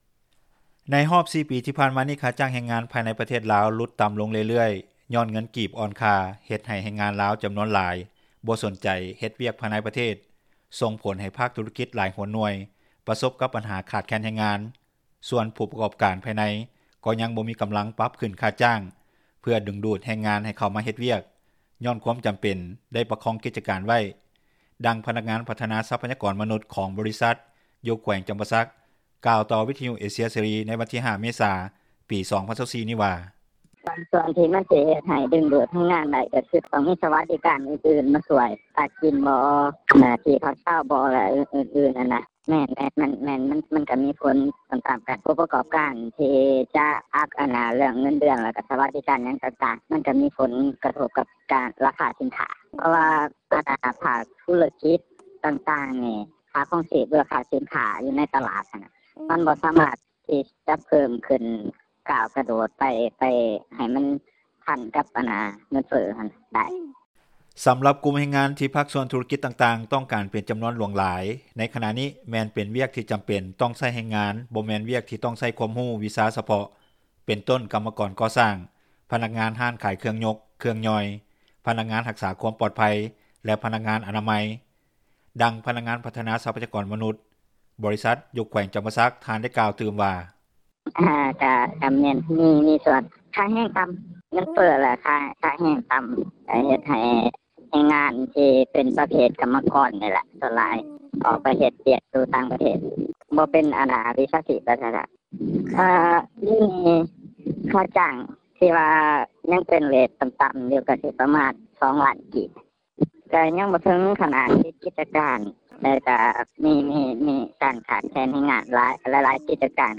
ດັ່ງນັກວິຊາການ ດ້ານເສດຖະສາດ ທ່ານກ່າວຕໍ່ວິທຍຸເອເຊັຽເສຣີ ໃນມື້ດຽວກັນວ່າ:
ດັ່ງຄົນງານລາວ ທີ່ຕ້ອງການເດີນທາງໄປເຮັດວຽກຢູ່ປະເທດໄທ ຍານາງກ່າວວ່າ:
ດັ່ງພະນັກງານບໍລິສັດຈັດຫາງານ ນາງກ່າວວ່າ: